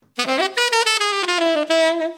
次中音萨克斯 D大调 110bpm " bebop2 4beat Dmaj 110 - 声音 - 淘声网 - 免费音效素材资源|视频游戏配乐下载
次中音萨克斯风片段，用Behringer B1麦克风通过Behringer 802A调音台录制到装有Terratec EWX2496声卡的PC上，运行CoolEditPro软件。